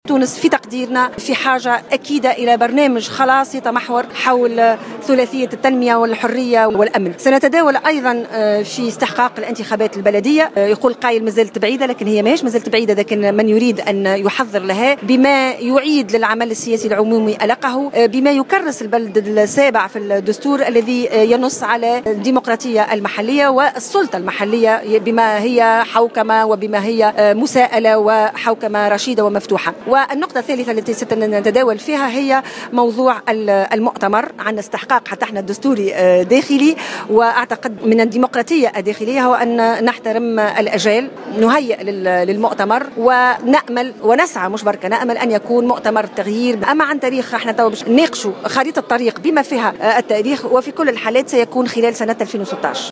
وقالت إن حزبها يدرس استحقاقات الانتخابات البلدية وإنه سيعمل على تكريس الديمقراطية المحلية من خلال هذه الانتخابات، وذلك في تصريحات اليوم السبت بسوسة، بمناسبة انعقاد ندوة إطارات الحزب الجمهوري، اكدت فيها أن الاجتماع سيركز على الإستعدادات لموعد انعقاد المؤتمر القادم للجمهوري الذي من المنتظر أن ينعقد قبل موفى 2016 .